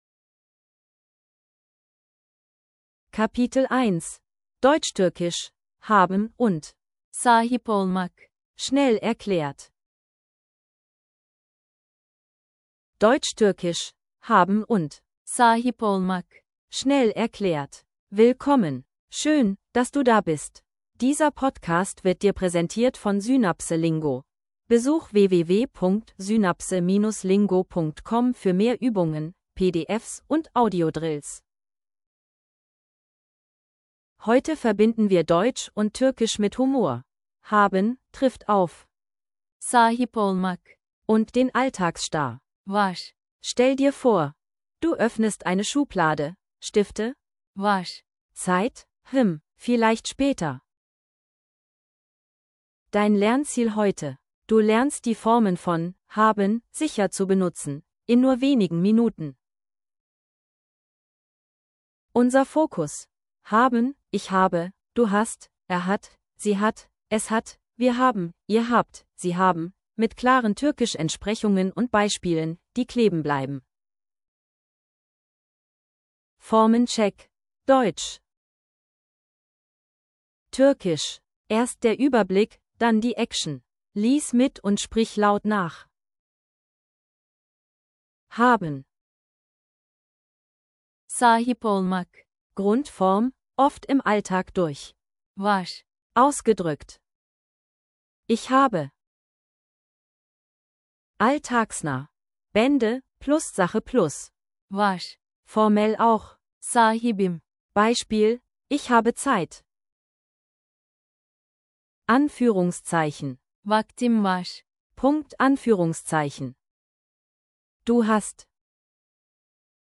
Audio zum Mitsprechen & Wiederholen